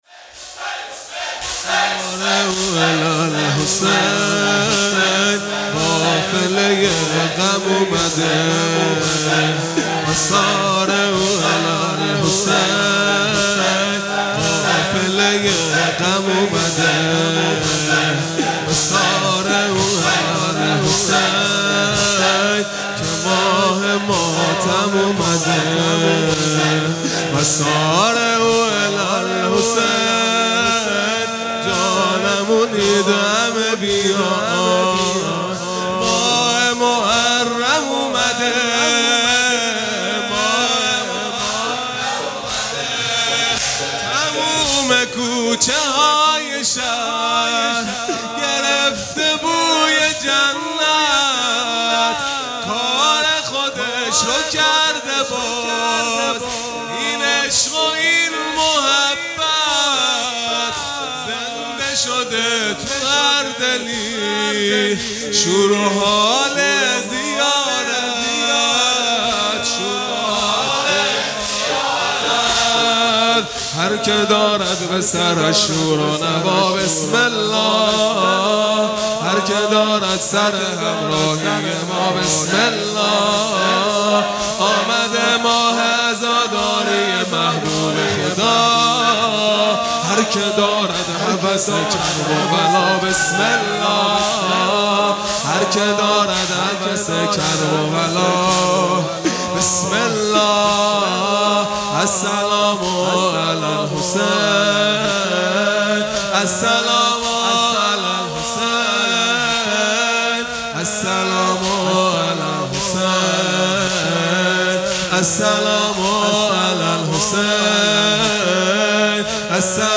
خیمه گاه - هیئت بچه های فاطمه (س) - زمینه | و سارعو علی الحسین قافلۀ غم اومده | 7 مرداد ۱۴۰۱
محرم ۱۴۴۴ | شب اول